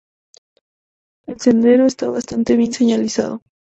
sen‧de‧ro
/senˈdeɾo/